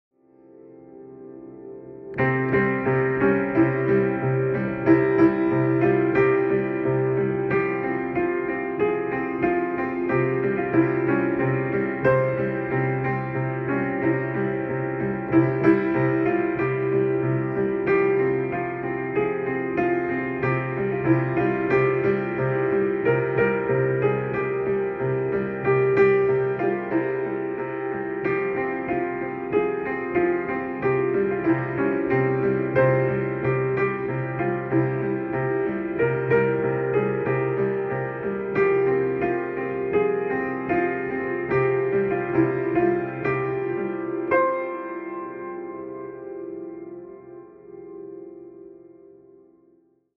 Noodling On The Piano In Sound Effects Free Download